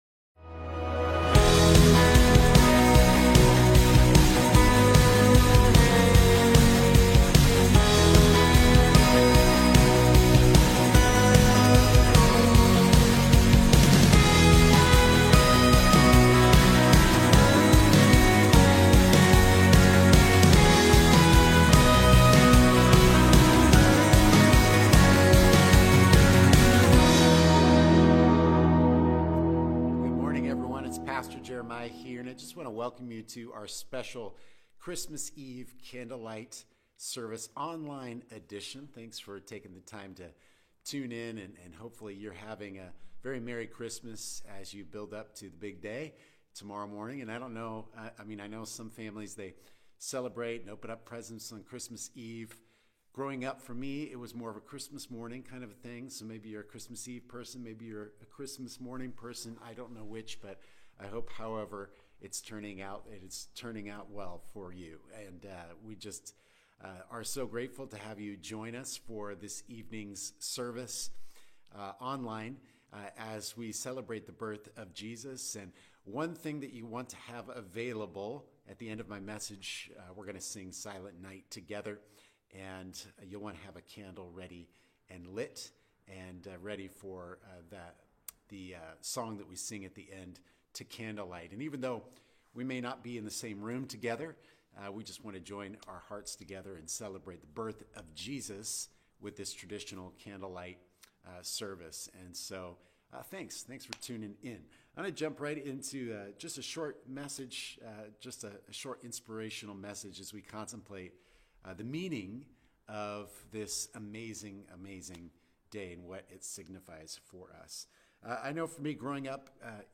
Christmas Eve Candlelight Service – Westside Church | Seattle, WA
Join us as we celebrate the birth of our Savior Jesus Christ with a traditional Candlelight service.
Westside-Church-Christmas-Eve-Candlelight-Service.mp3